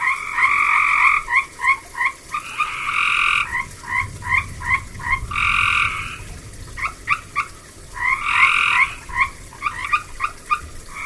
Copes Gray Tree Frog
Many sounds of Copes Gray Tree Frog.
frgalarmlong.mp3